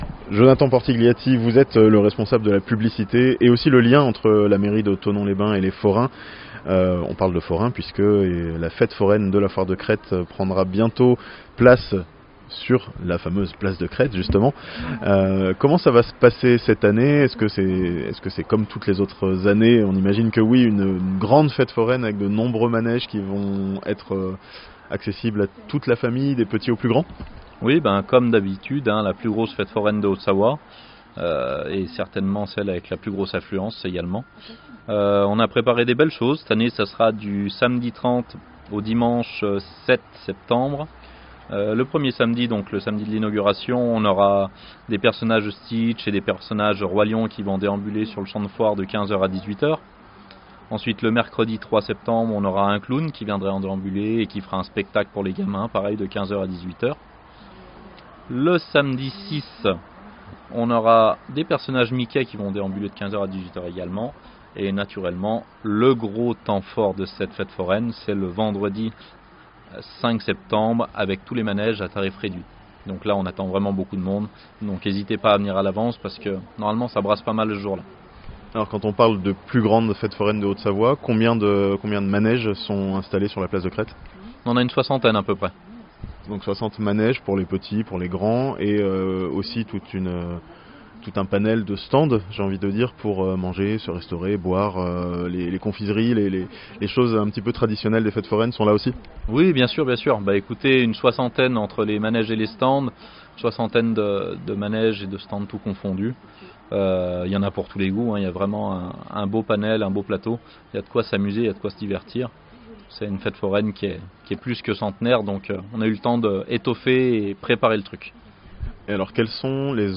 La plus grande fête foraine de Haute-Savoie ouvre ce samedi, à Thonon (interview)